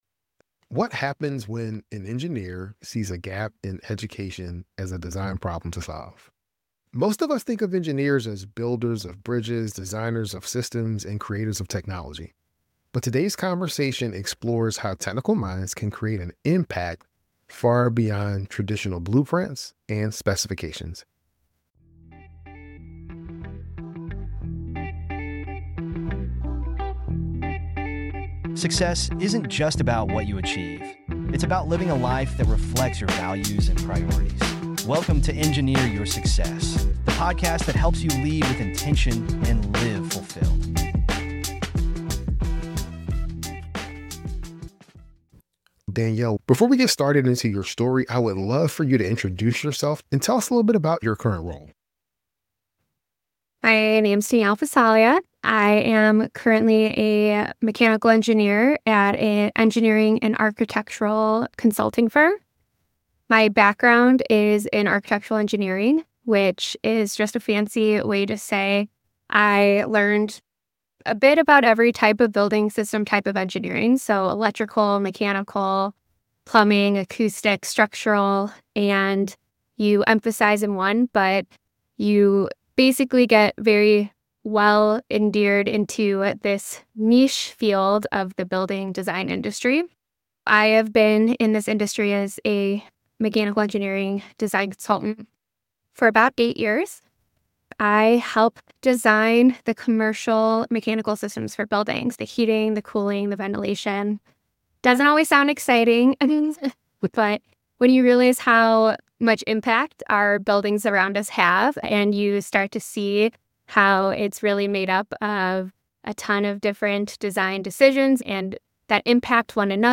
This conversation bridges technical expertise with purpose-driven leadership to show how engineers can impact the next generation.